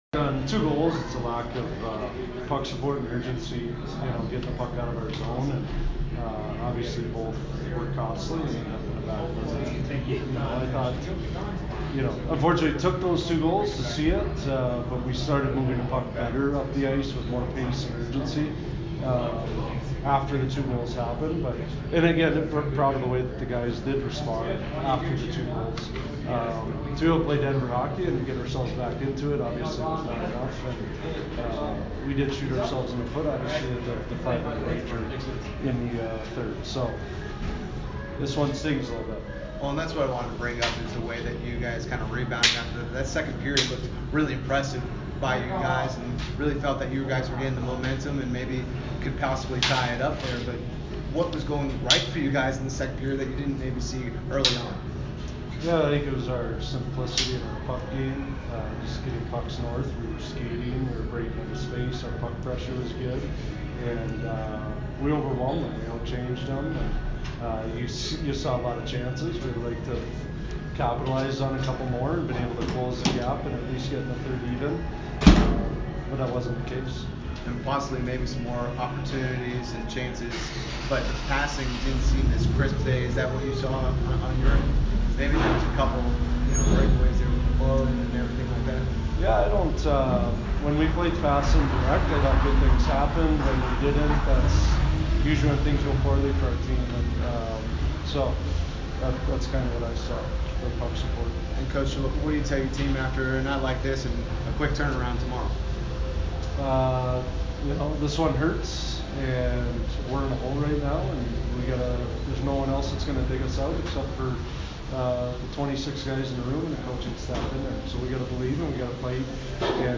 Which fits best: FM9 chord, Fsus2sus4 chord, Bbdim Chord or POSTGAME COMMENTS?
POSTGAME COMMENTS